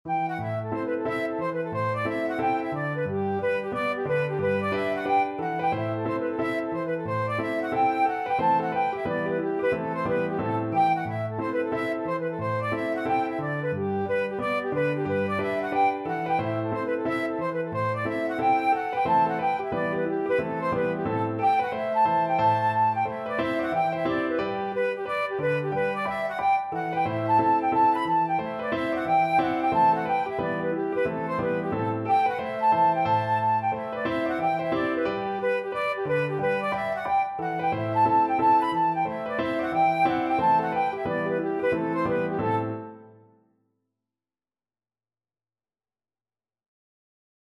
Flute
Traditional Music of unknown author.
G major (Sounding Pitch) (View more G major Music for Flute )
4/4 (View more 4/4 Music)
Presto =c.180 (View more music marked Presto)